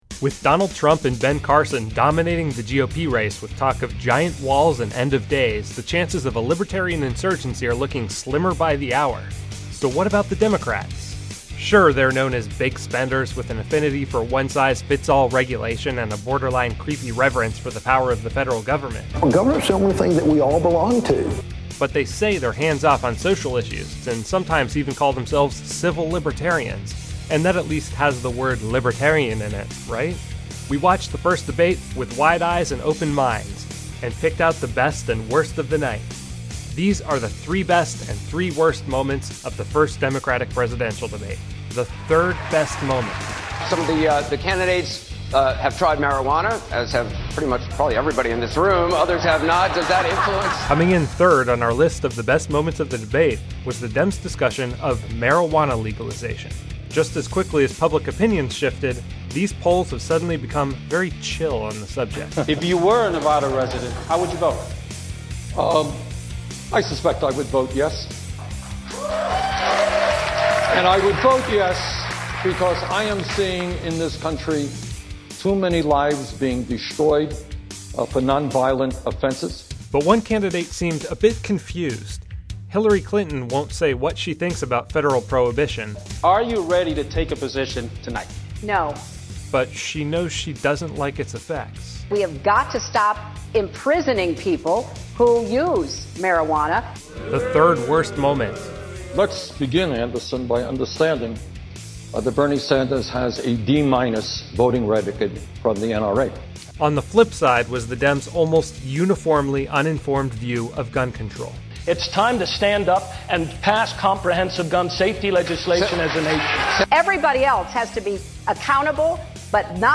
The crowd may have loved her unapologetic posture, but the standing ovation they delivered doesn't change the fact that Clinton is still under federal investigation for compromising classified information while serving as Secretary of State.